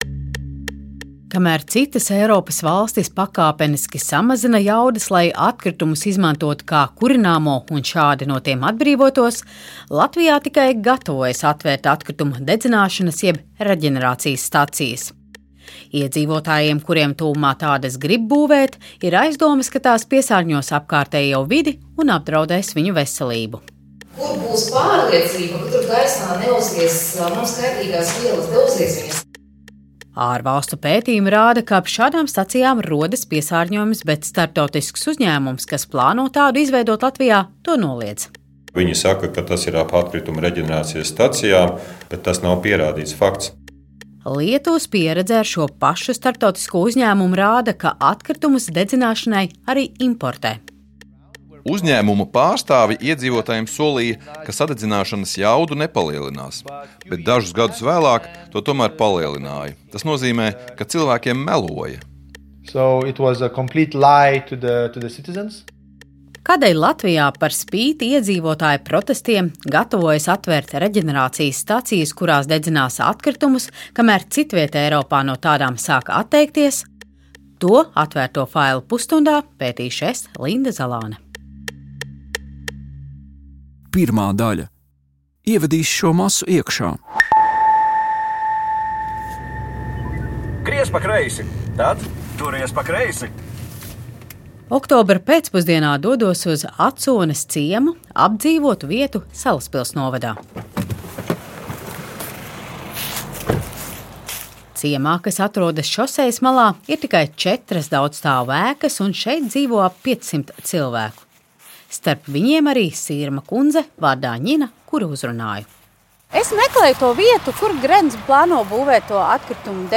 No politikas līdz biznesam, par sociālo dzīvi un krimināldrāmām – pētnieciskais raidījums “Atvērtie faili” iedziļinās mūsu laika svarīgākajos notikumos. Katrs raidījums ir dokumentāls audiostāsts par procesiem un cilvēkiem, kas veido mūsu sabiedrisko dzīvi.